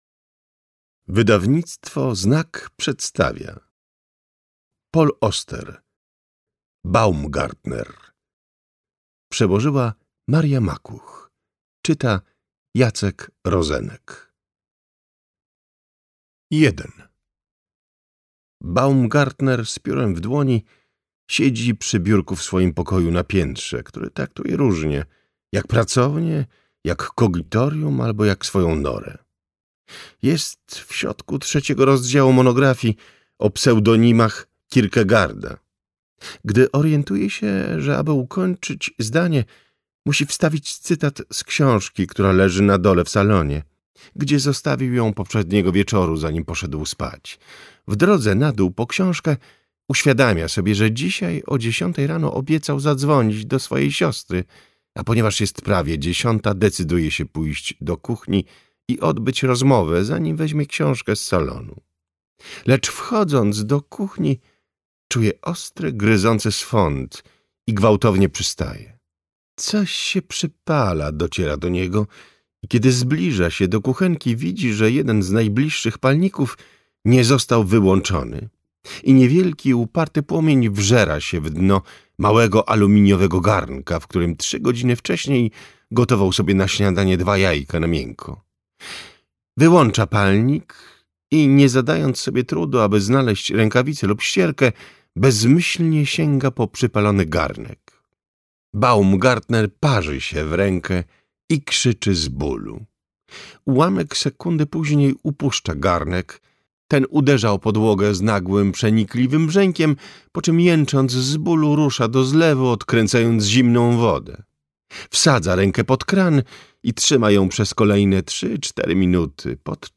Baumgartner - Paul Auster - audiobook